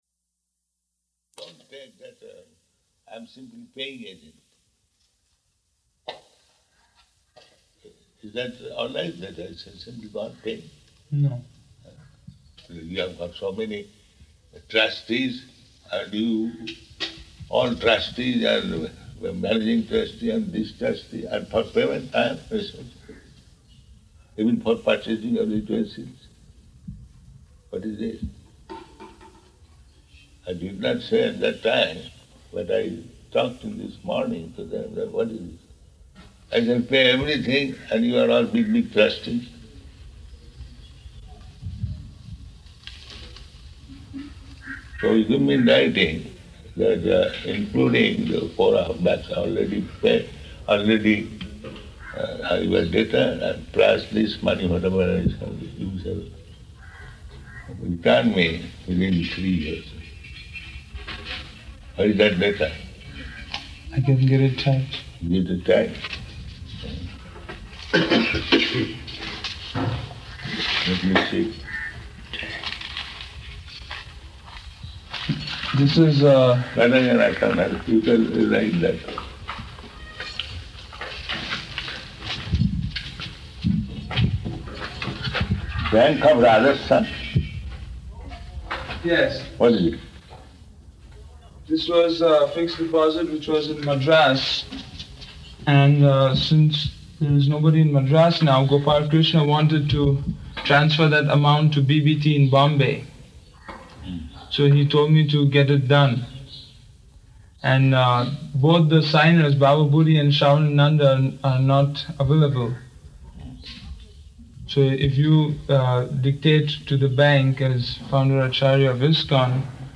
Room Conversation
Room Conversation --:-- --:-- Type: Conversation Dated: December 16th 1976 Location: Hyderabad Audio file: 761216R1.HYD.mp3 Prabhupāda: Don’t take that I am simply paying agent.